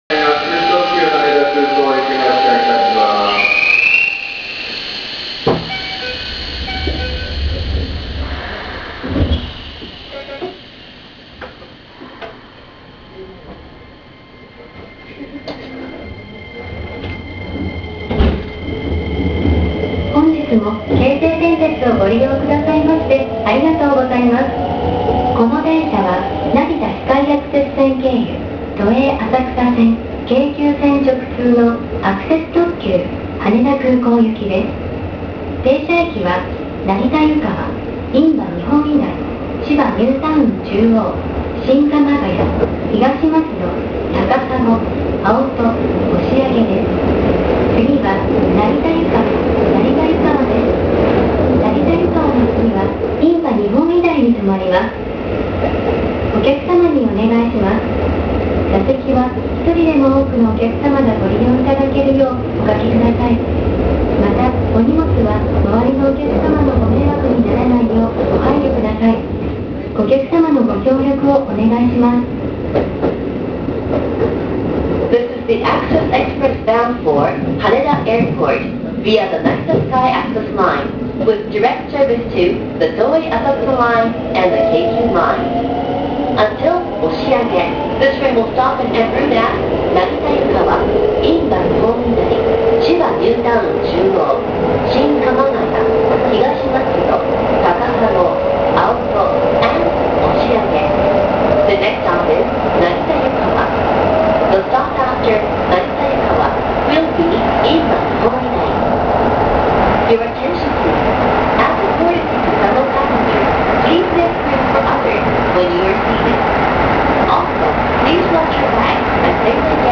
〜車両の音〜
・3050形走行音
【成田ｽｶｲｱｸｾｽ】空港第２ビル→根古屋信号場（4分49秒：1.53MB）
3000形と全く同じ東洋IGBTです。